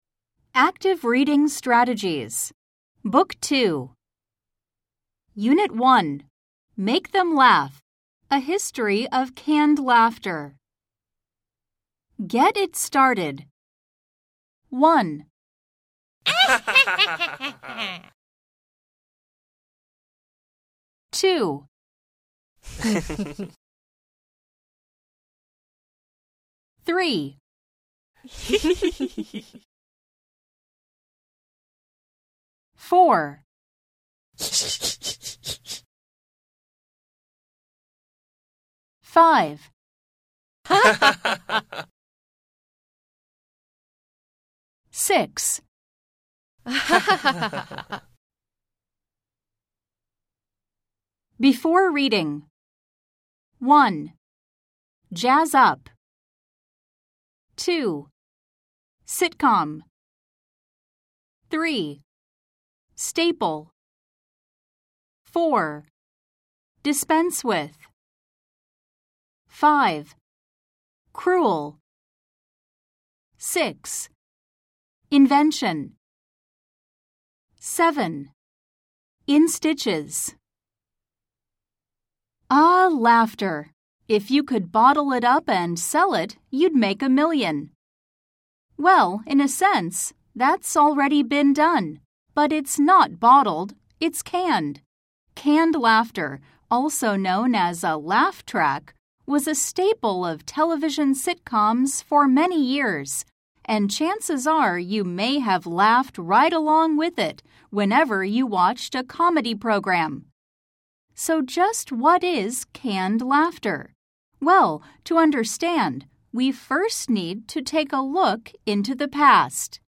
吹き込み Amer E